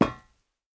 footsteps / rails
rails-11.ogg